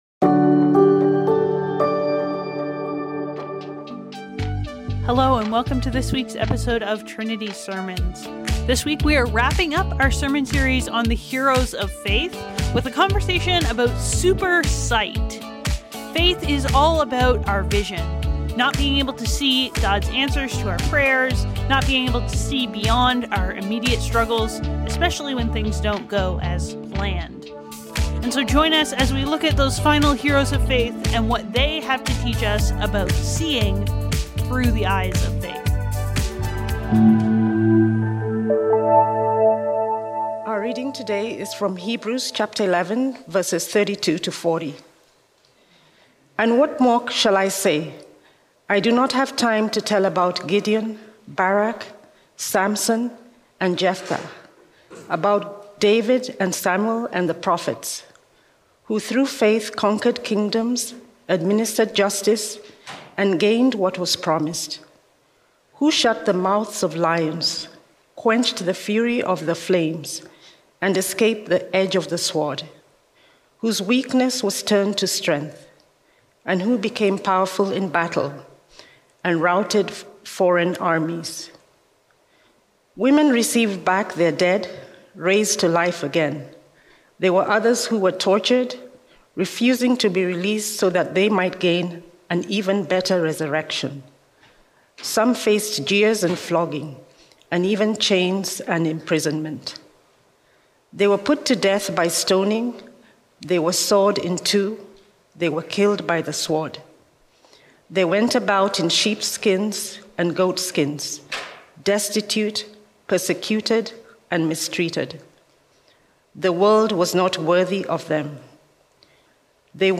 Trinity Streetsville - Seeing the Unseen | Heroes of Faith | Trinity Sermons